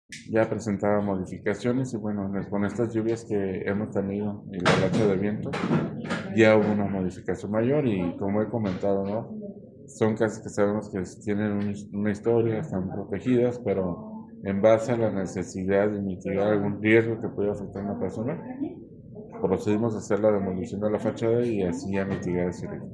AudioBoletines
Israel Martínez Negrete, coordinador municipal de Protección Civil y Bomberos, explicó que las lluvias recientes, así como las que se pronostican, fueron determinantes para acelerar las acciones preventivas.